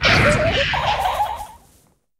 Cri de Scovilain dans Pokémon HOME.